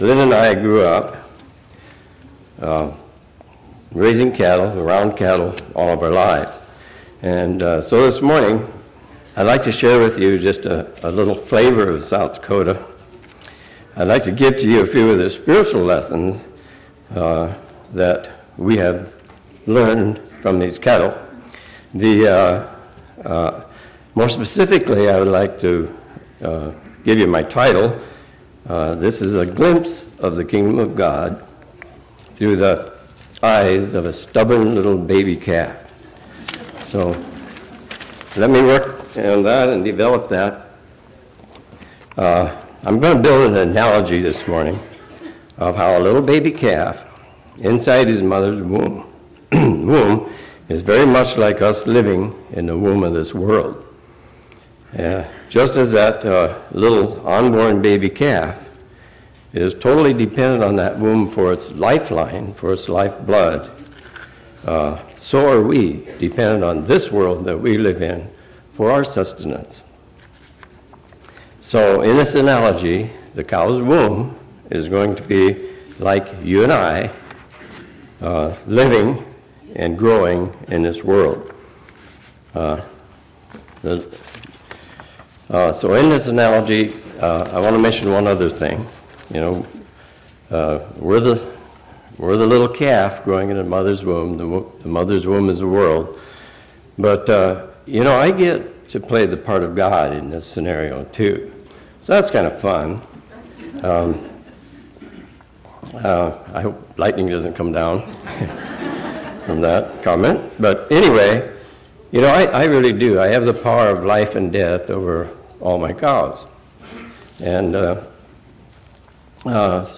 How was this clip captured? This sermon was given at the Lake George, New York 2018 Feast site.